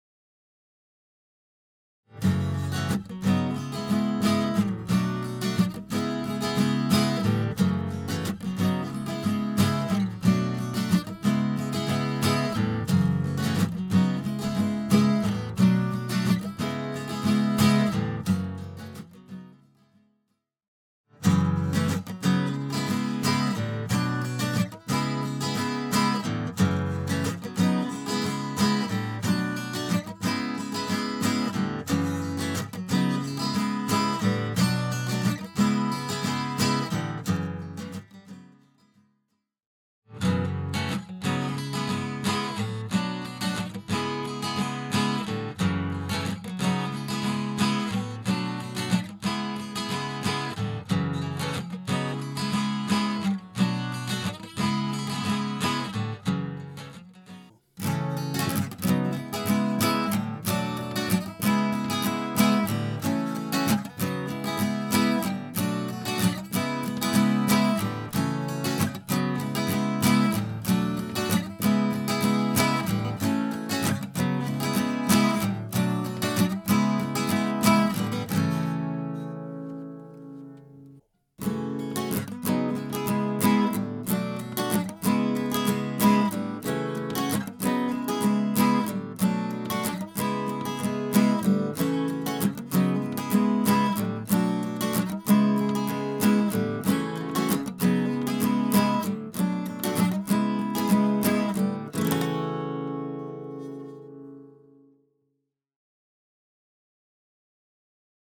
*add soudclip SM81, KSM27,MXL BCD, 849 at 12th and 849 at low bridge
** After some more tracking and spec research, the SM81 is almost double the sensitivity(louder) and the 849 needs a decent amount of gain for acoustics on this a acoustic guitar. SM81/PG81 have sparkle like the KSM44 where the 849 is more detailed and similar to the KSM32. It seems flat compared to the 81 which seems to have a more eq'd sound.
Acoustic Mic 81 27 bcd 849 12 an Bridge 2020 mixMP3.mp3